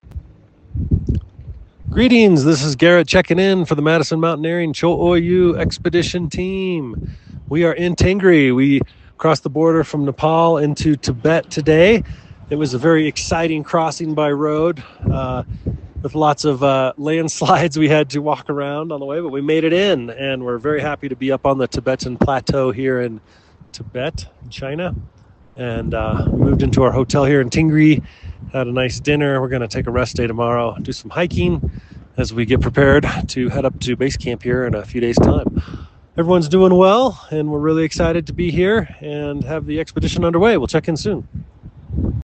• Enable the skill and add to your flash briefing to hear our daily audio expedition updates on select expeditions.